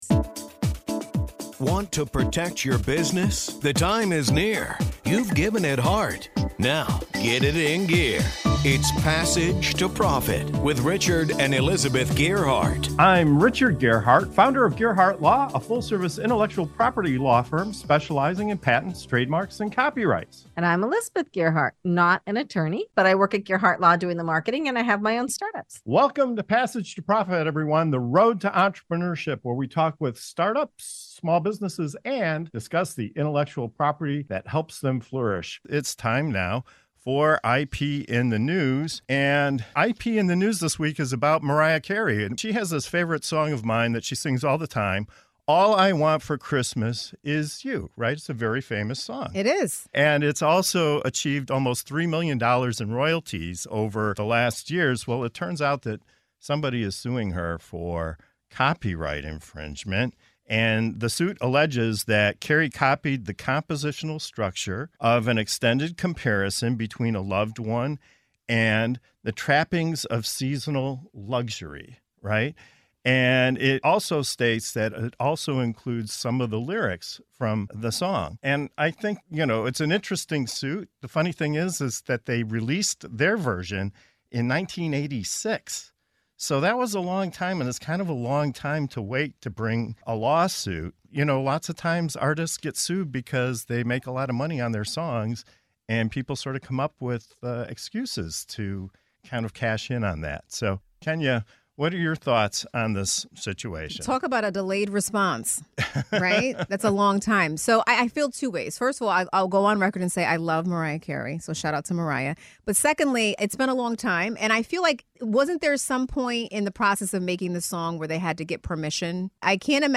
We delve into the $3 million royalties, a lawsuit alleging copyright infringement, and the peculiar 1986 version that surfaces after decades. With guests sharing thoughts on the statute of limitations, financial struggles, and the Ed Sheeran precedent, the podcast takes you on a jingle-filled journey through the legal complexities of the music industry.